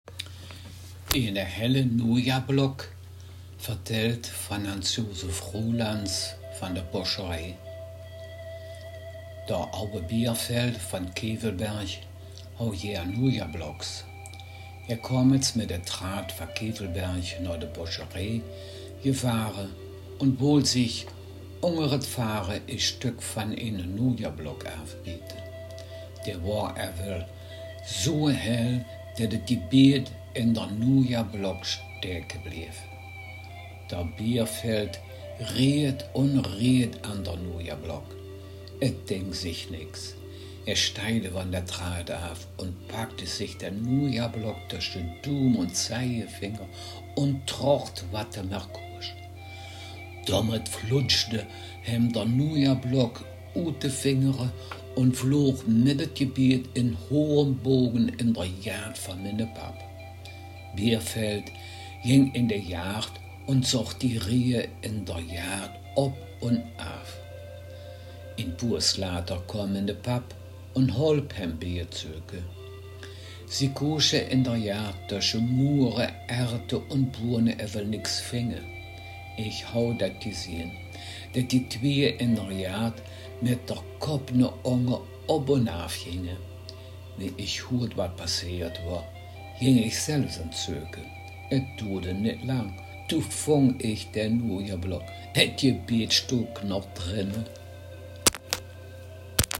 Gangelter-Waldfeuchter-Platt
Geschichte